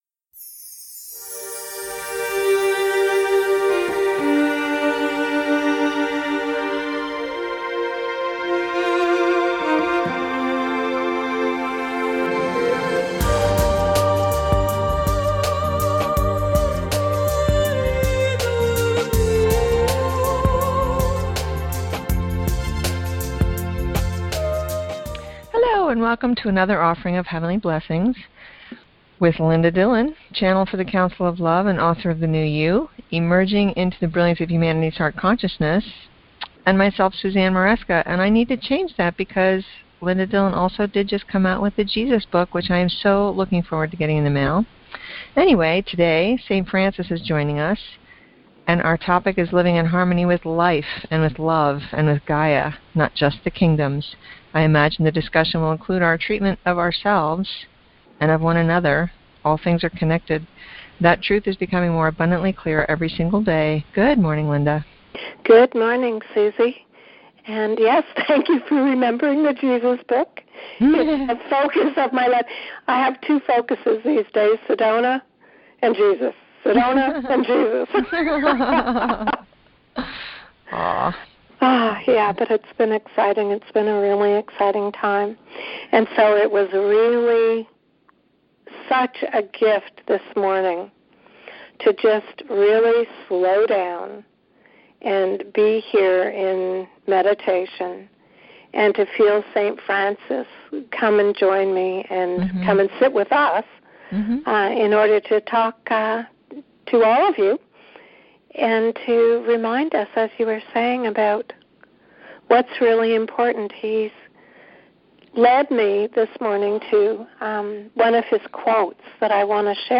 Host [Meditation from 13:10 to 20:02] https